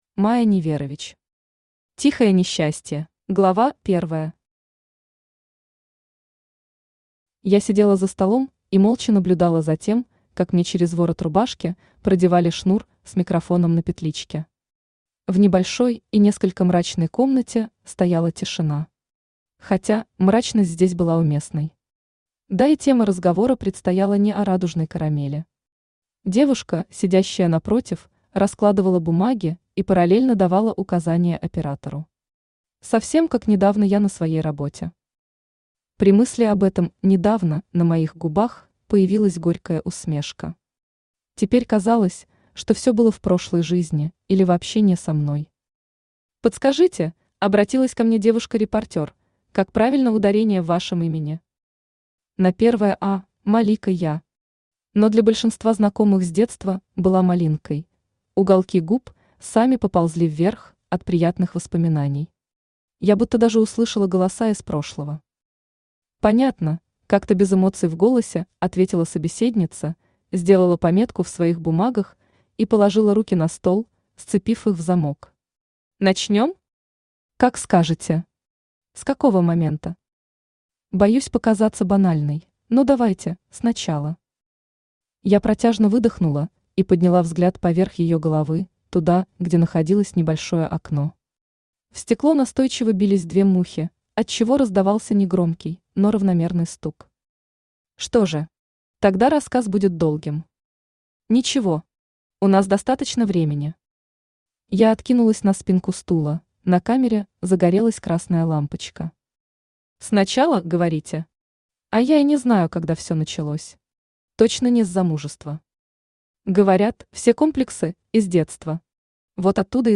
Аудиокнига Тихое НЕсчастье | Библиотека аудиокниг
Aудиокнига Тихое НЕсчастье Автор Майя Неверович Читает аудиокнигу Авточтец ЛитРес.